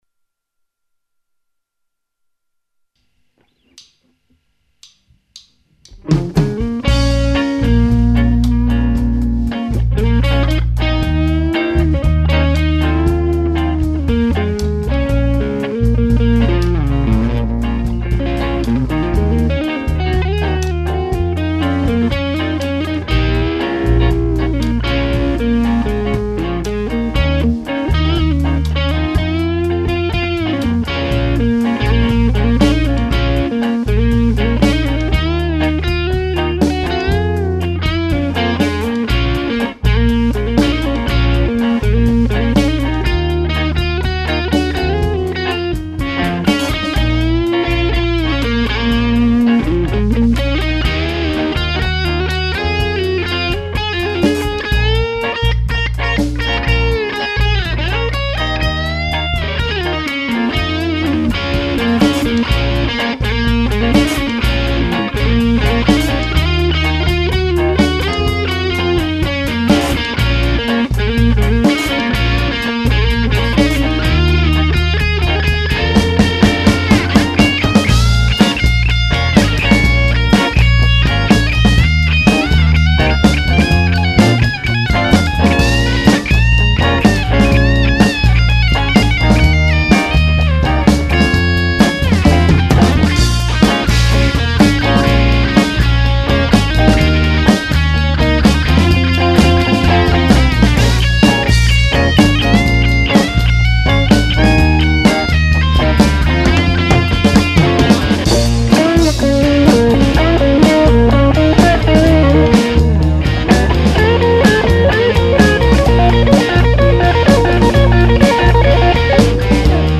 Wir machen Blues,Bluesrock,Classicrock!
drums
bass